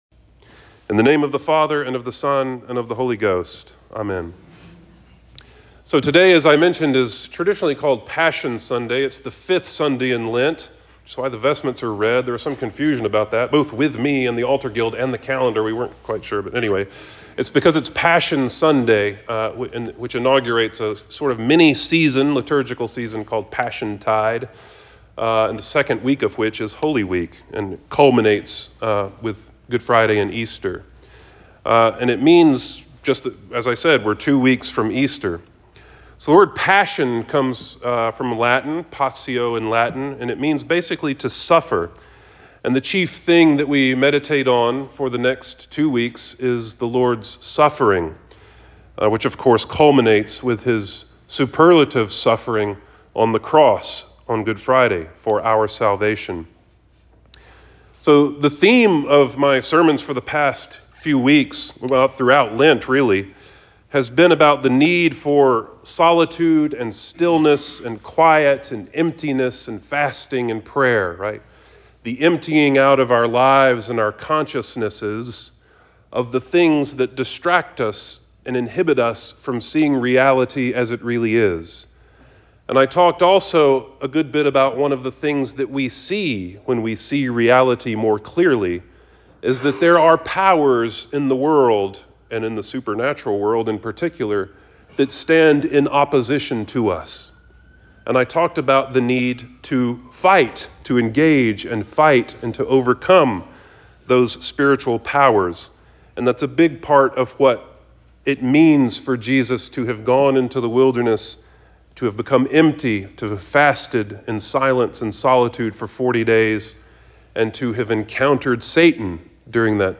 All Saints Sermons